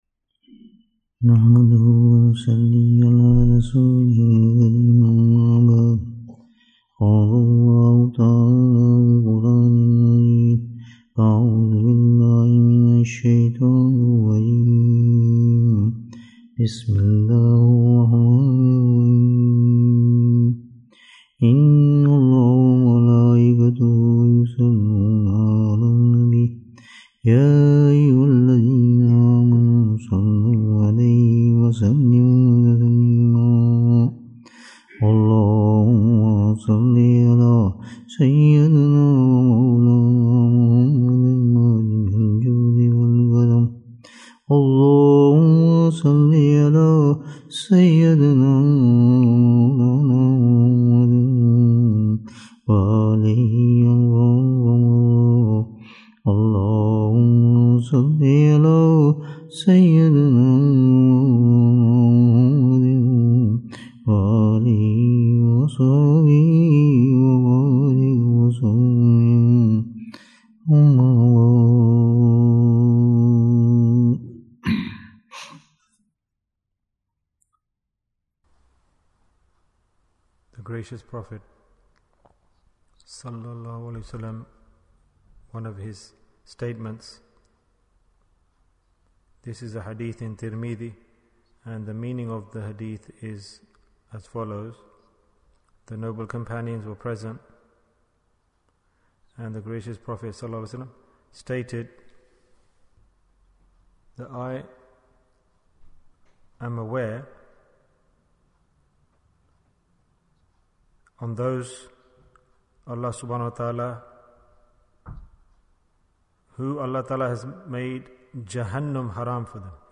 Two Precious Advices Bayan, 55 minutes5th January, 2023